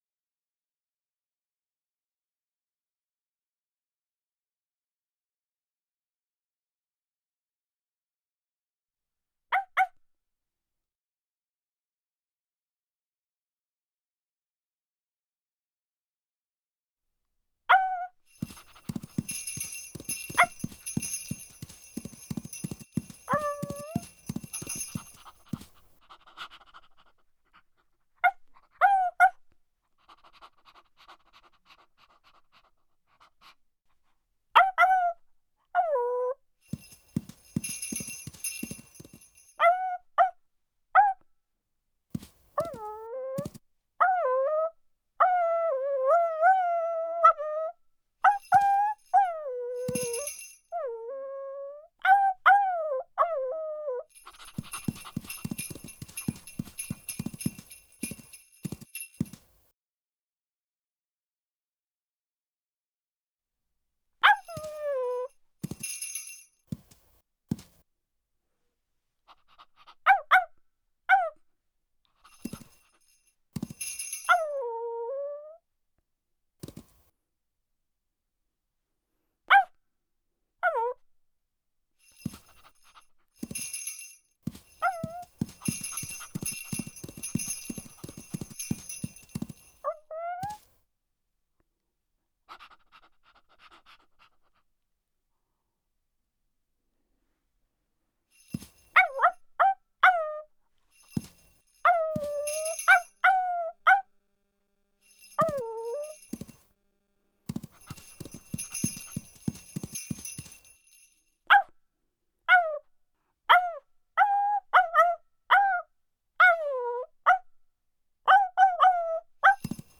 SFX_Scene01_Dog.ogg